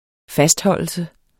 Udtale [ ˈfasdˌhʌlˀəlsə ]